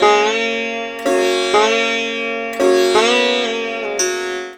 SITAR LINE52.wav